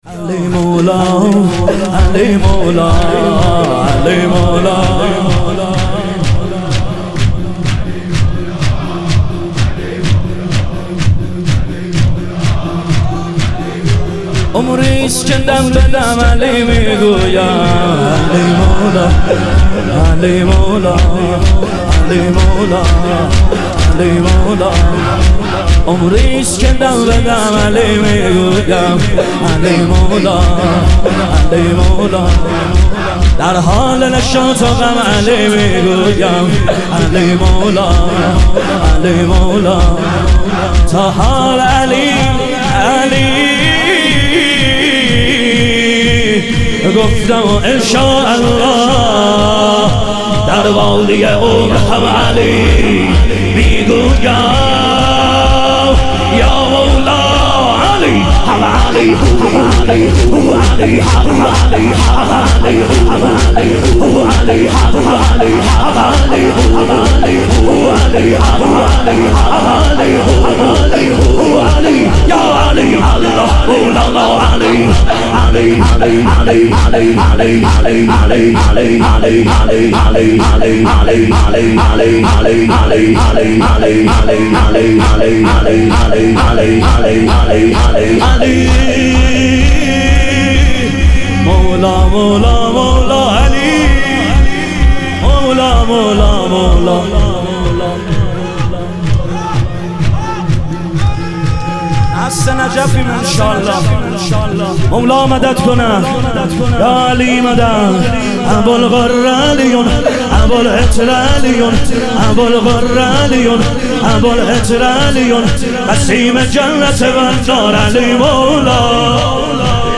حرکت کاروان سیدالشهدا علیه السلام - شور